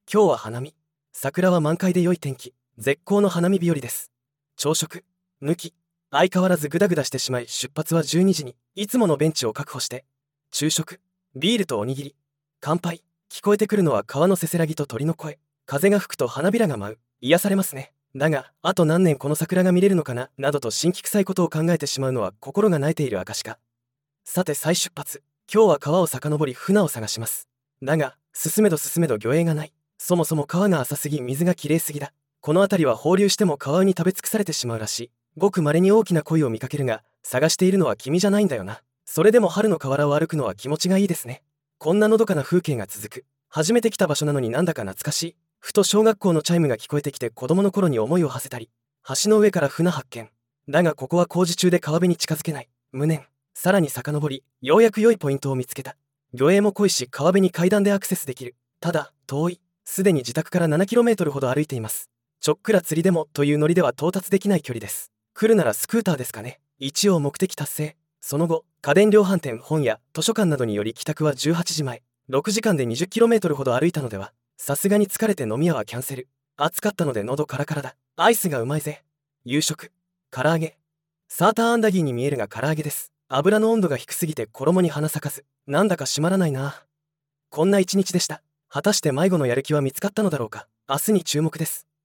桜は満開で良い天気。
花見
聞こえてくるのは川のせせらぎと鳥の声。